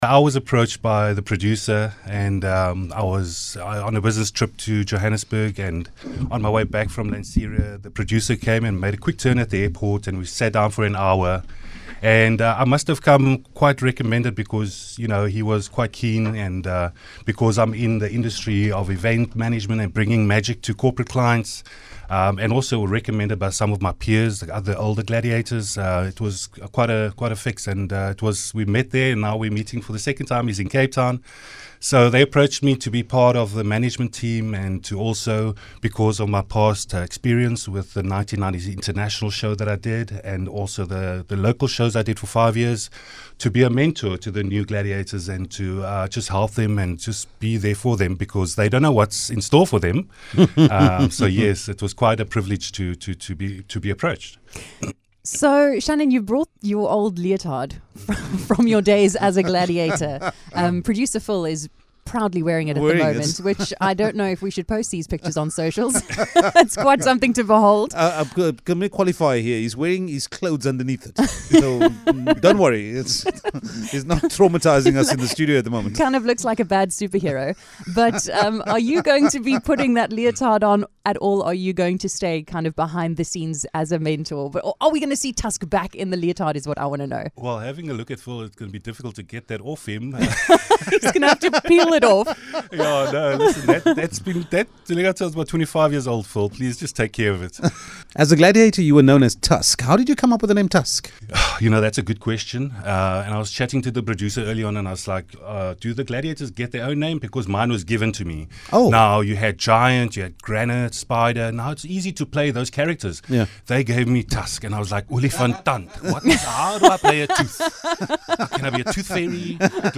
a chat about the new instalment of the show